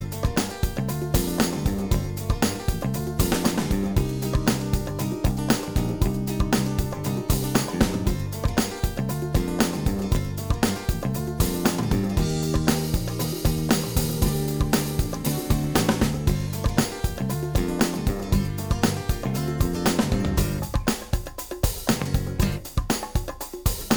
Minus Harmonica Pop (1970s) 3:21 Buy £1.50